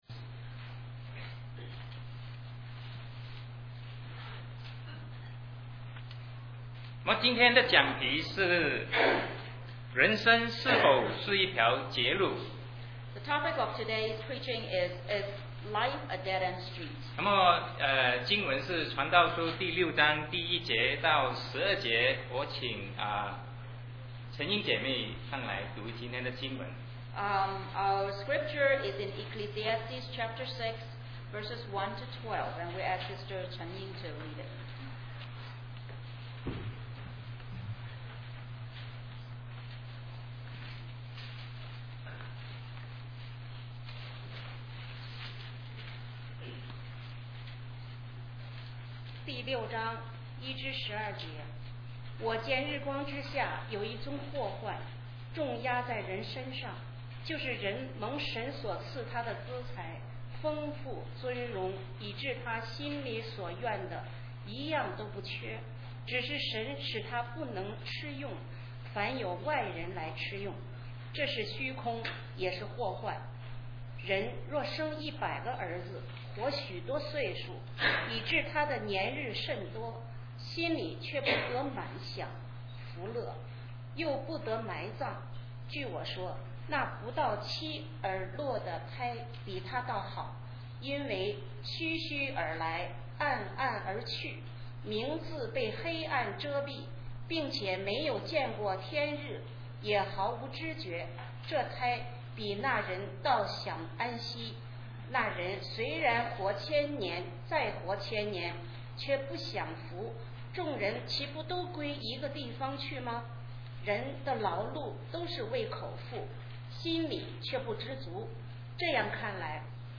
Sermon 2007-11-25 Is Life a Dead End Street?